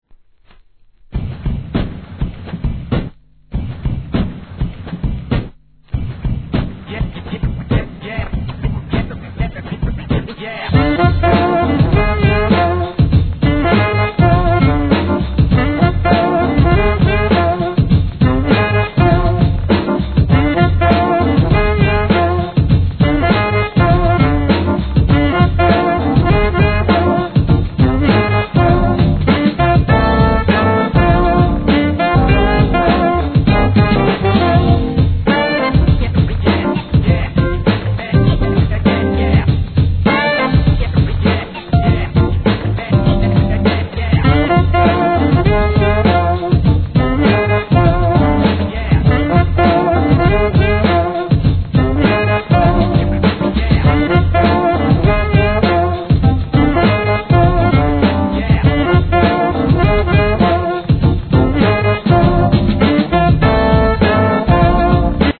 SOUL/FUNK/etc... 店舗 ただいま品切れ中です お気に入りに追加 UK JAZZシンーンのSAX奏者!!